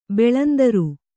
♪ beḷandaru